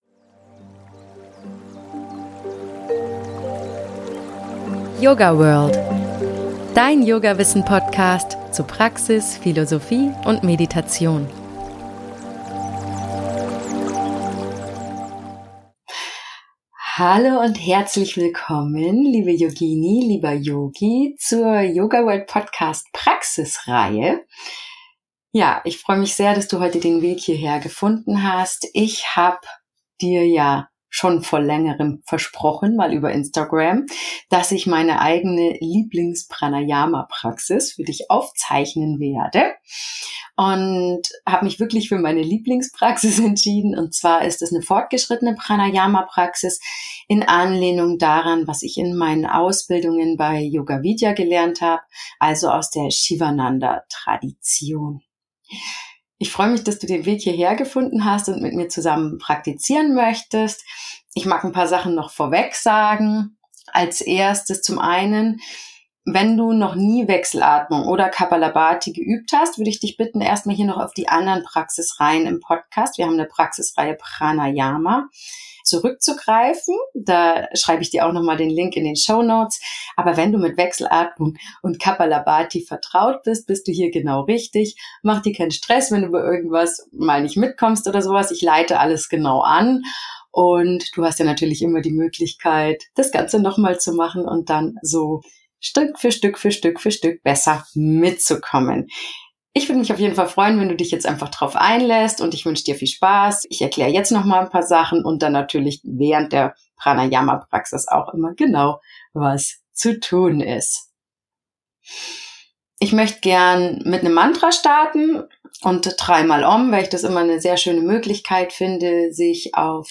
Die Session startet mit einem Schutz-Mantra und dreimaligem „Om“ – ein sanfter Übergang in den meditativen Zustand, der dich optimal auf die Praxis vorbereitet. In den ersten Übungen vertiefst du dich in Kapalabhati, die reinigende „Schädelglanz-Atemtechnik“, die deinen Körper mit frischer Energie auflädt.